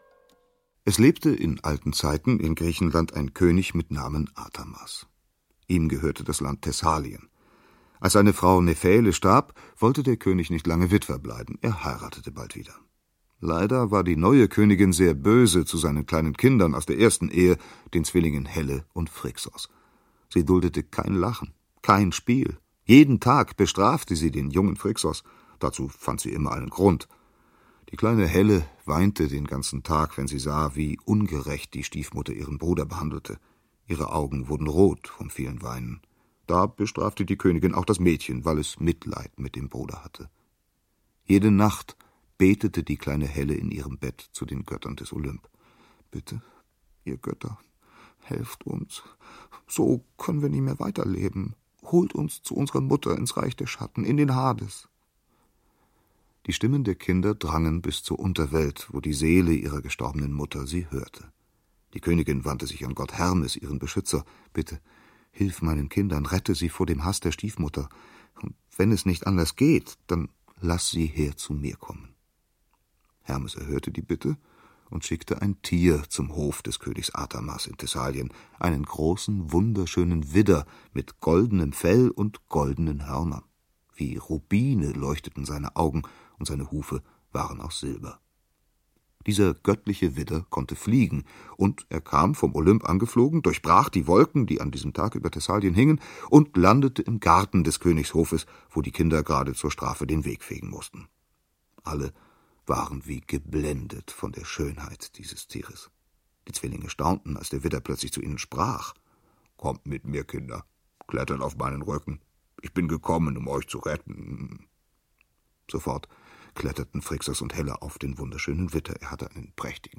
Lese- und Medienproben